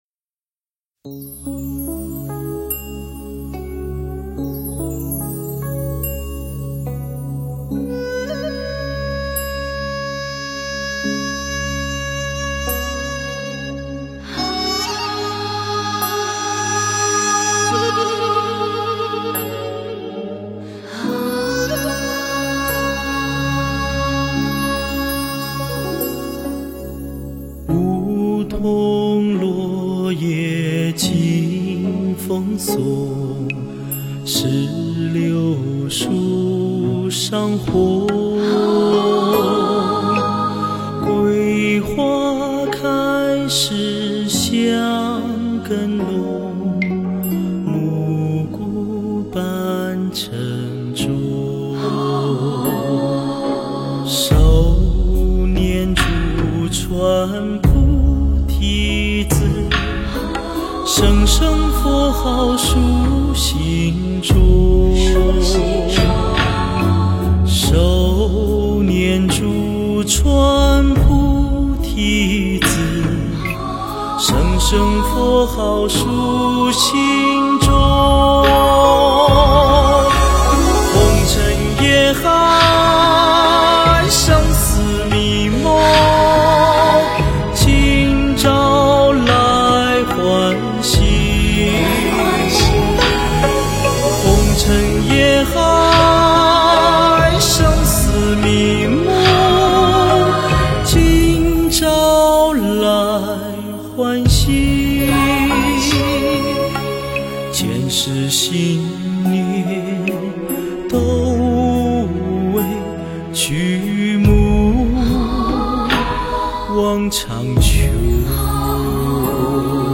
佛音 凡歌 佛教音乐 返回列表 上一篇： 心无罣碍(梵文唱诵-轻快版